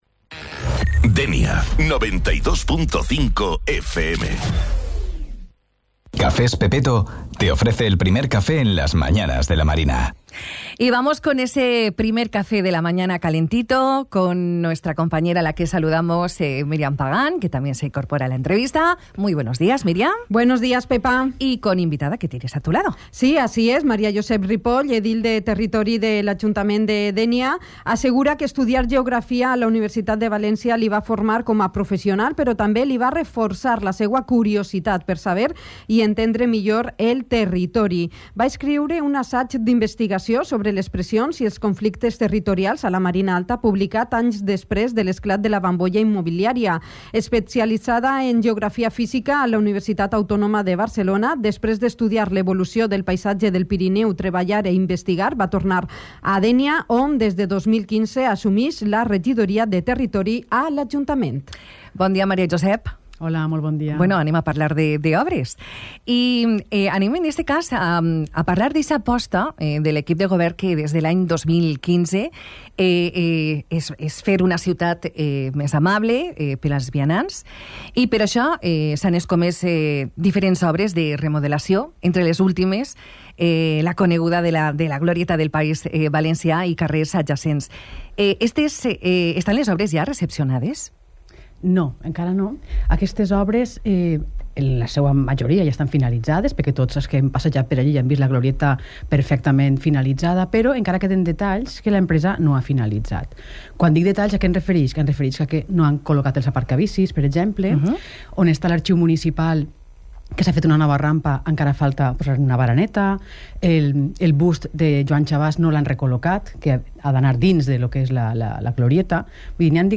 Con el inicio del nuevo año, ha visitado el ‘primer café’ de Dénia FM, la edil de Territorio y Calidad Urbana en el Ayuntamiento dianense, Maria Josep Ripoll para profundizar en aquellas obras que se han finalizado en la ciudad, las que están en marcha, las que ya se han licitado y las que vendrán en un futuro.
Entrevista-Maria-Josep-Ripoll.mp3